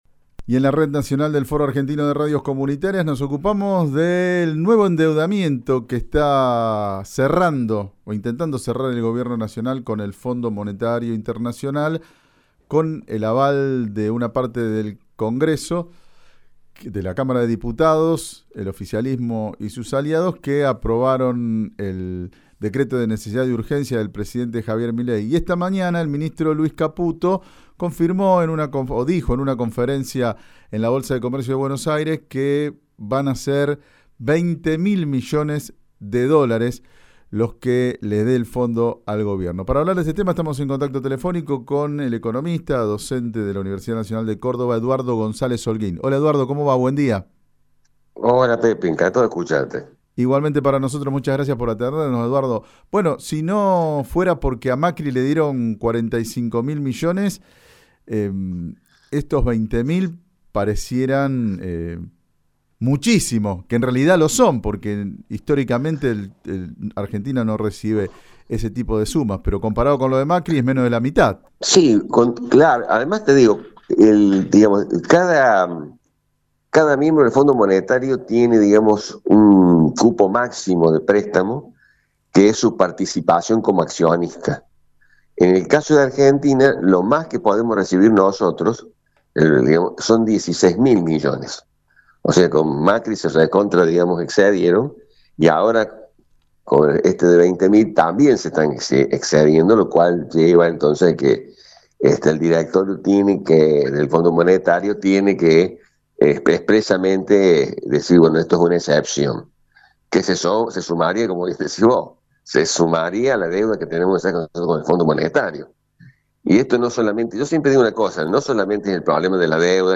señaló en diálogo con el Informativo FARCO que este nuevo endeudamiento evidencia la inconsistencia de la política económica de Caputo y Milei.